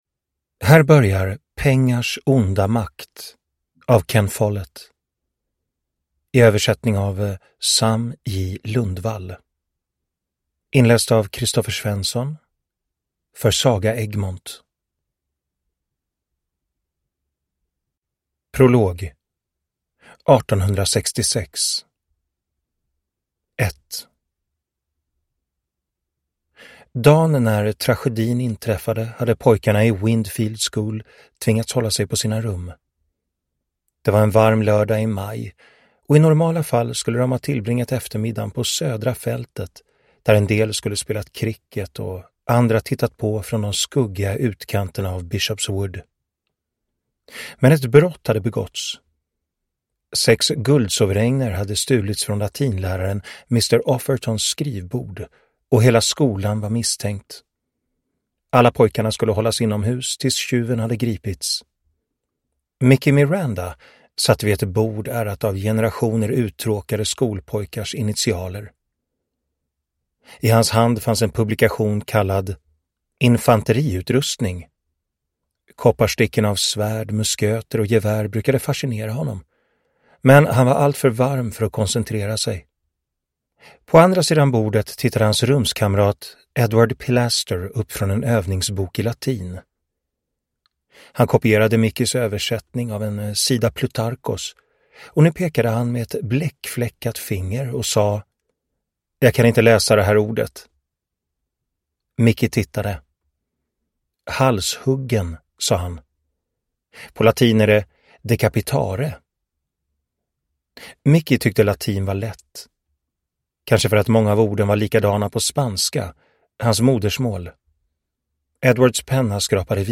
Ken Folletts succéthriller “Pengars onda makt” för första gången som ljudbok på svenska.